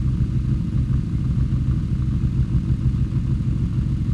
v8_06_idle.wav